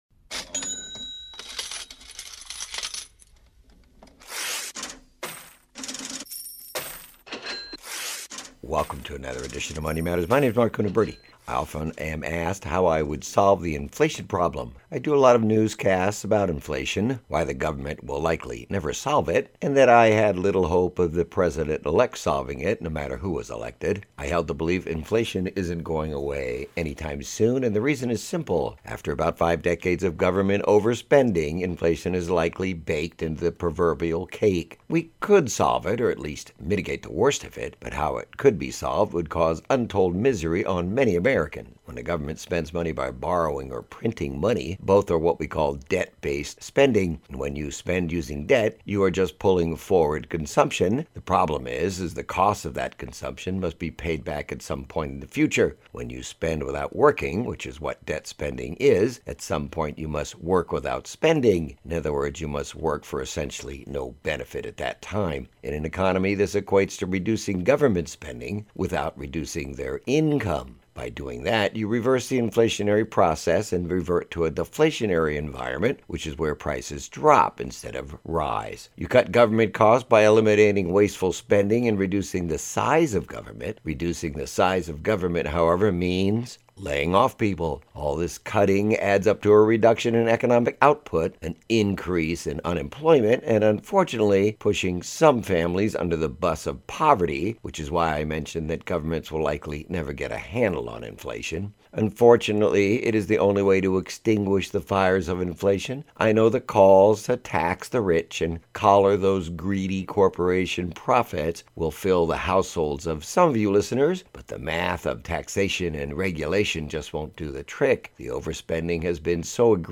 2047 #2047 Reducing your Cal Fair Fire Insurance Premium Costs - Yes it may be possible to reduce your fire insurance costs Great newscast